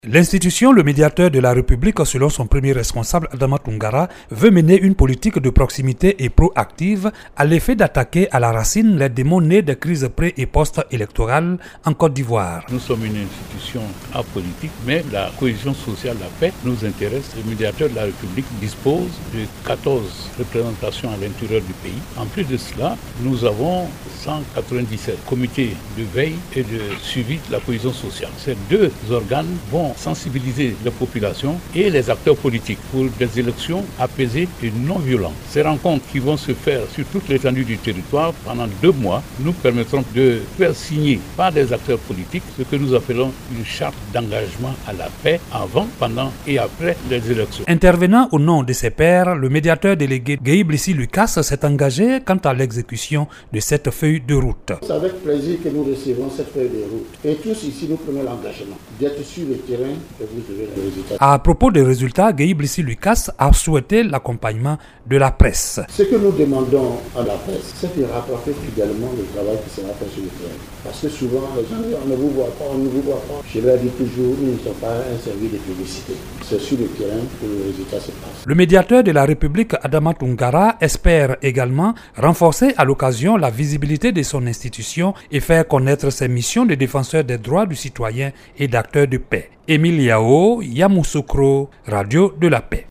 En ligne de Yamoussoukro